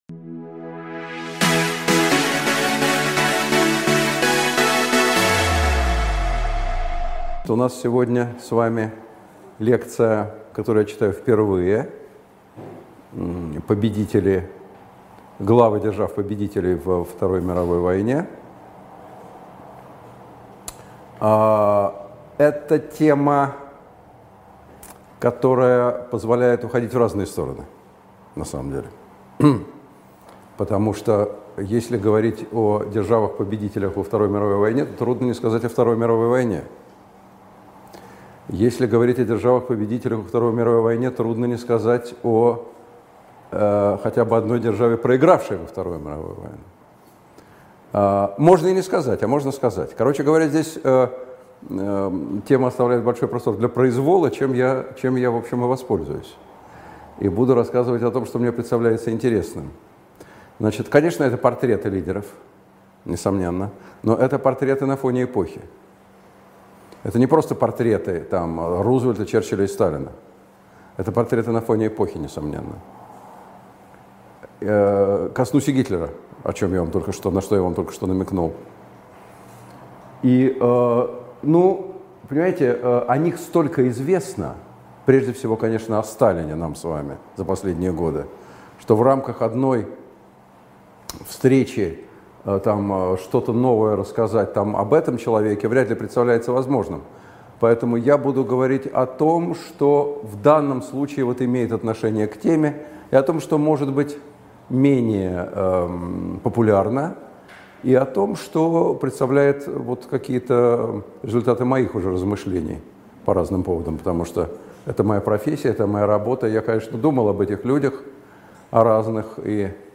Аудиокнига Сокрушившие фашизм. Лидеры победивших держав | Библиотека аудиокниг
Лидеры победивших держав Автор Николай Сванидзе Читает аудиокнигу Николай Сванидзе.